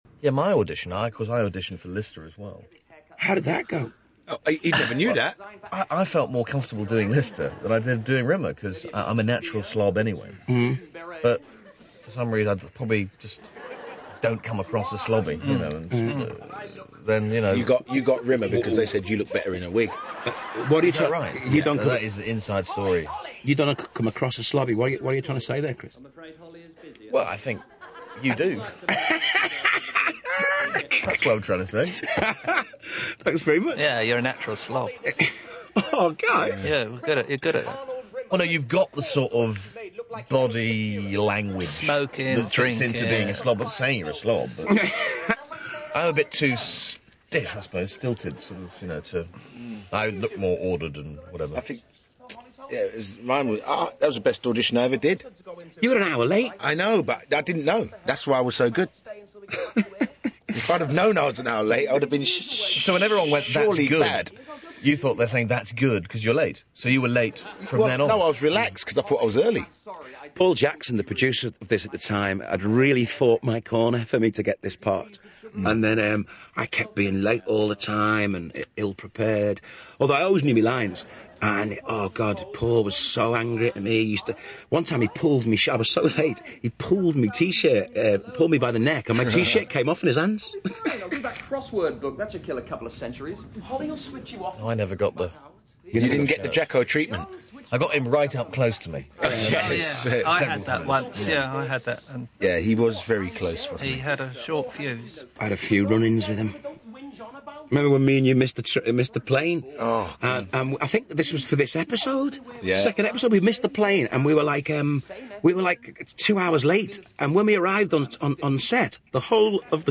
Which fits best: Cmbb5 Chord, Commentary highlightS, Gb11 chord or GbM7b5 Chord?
Commentary highlightS